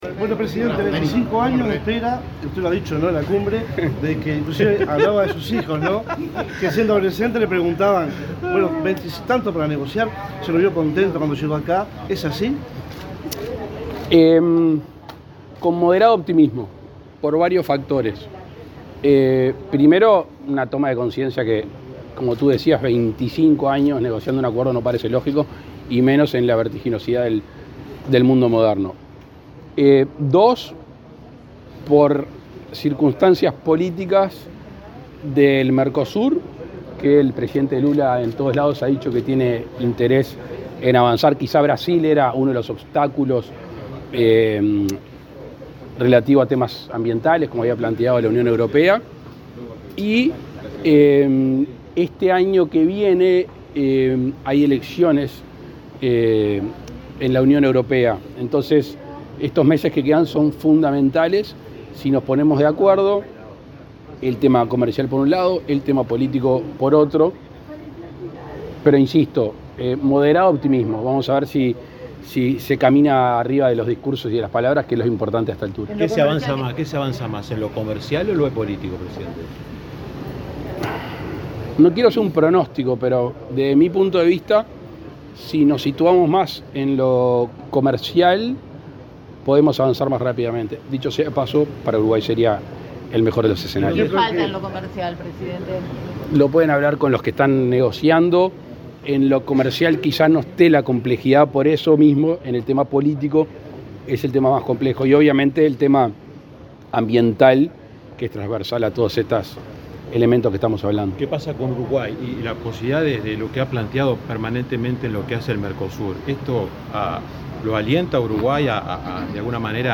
Declaraciones del presidente Luis Lacalle Pou
El presidente de la República, Luis Lacalle Pou, dialogó con la prensa tras arribar al país de su viaje oficial a Bruselas, Bélgica.